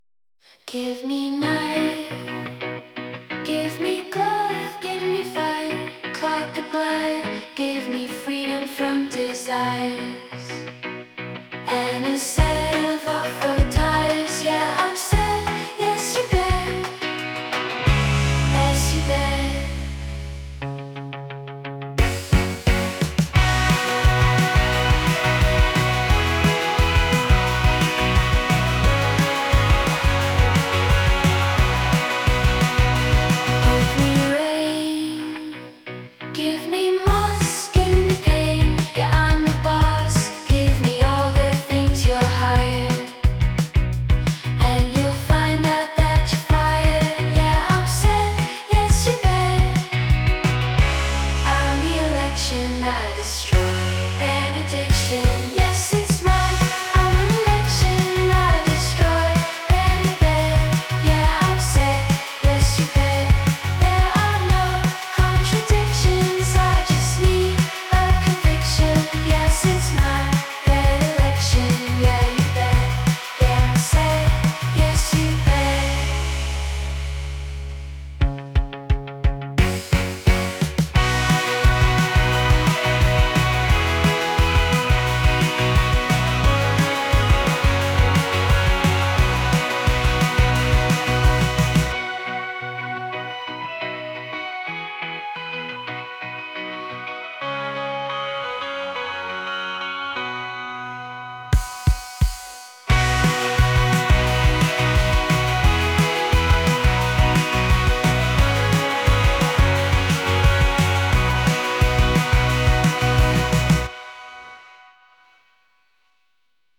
Featuring an old track with drums programmed on an Analog Rytm mk1.